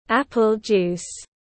Nước táo ép tiếng anh gọi là apple juice, phiên âm tiếng anh đọc là /ˈæp.əl ˌdʒuːs/